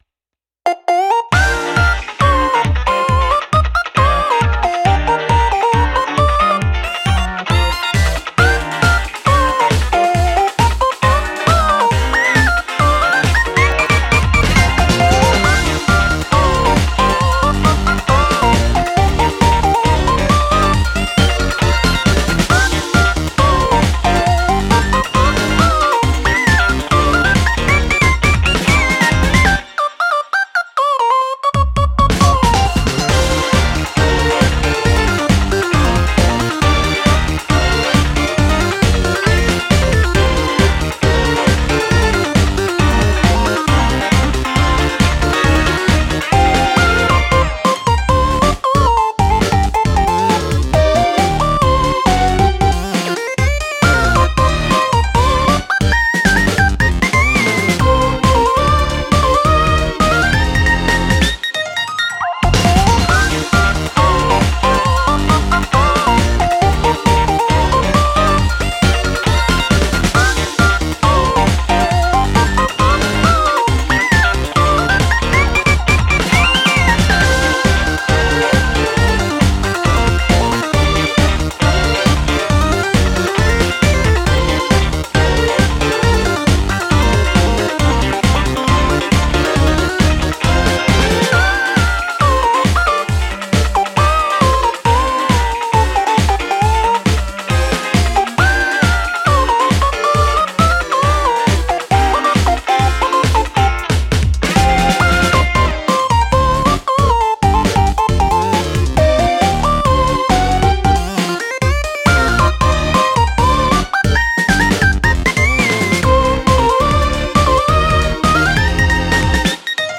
/ インスト
ループ用音源（BPM=136）